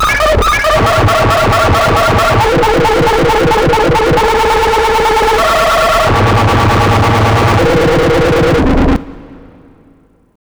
OLDRAVE 7 -L.wav